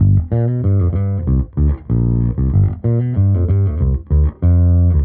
Index of /musicradar/dusty-funk-samples/Bass/95bpm
DF_JaBass_95-G.wav